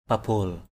/pa-bo:l/ (đg.) vun lên, thắt = entasser, faire un tas. pabol rak F%_bL rK vun mộ = entasser, la terre sur la tombe, faire une tombe. pabol bangu F%_bL bz~%...